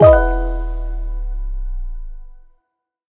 menu-freeplay-click.ogg